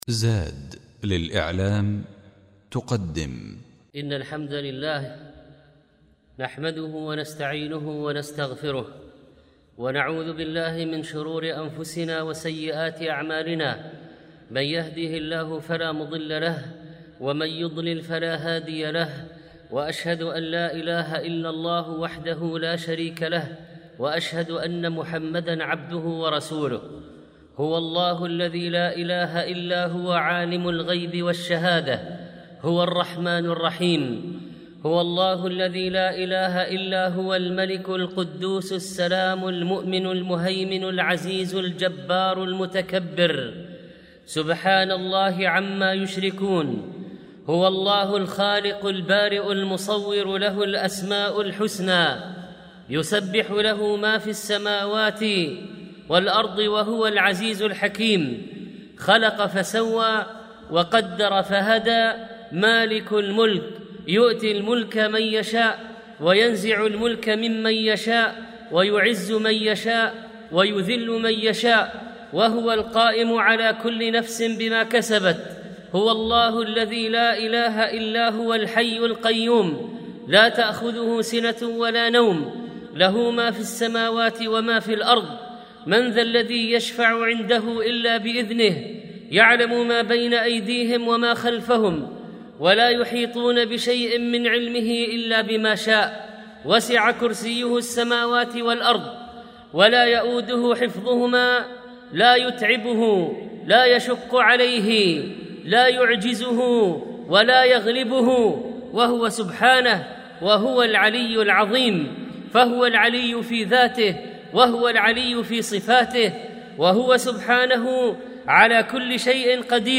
الموقع الرسمي للشيخ محمد صالح المنجد يحوي جميع الدروس العلمية والمحاضرات والخطب والبرامج التلفزيونية للشيخ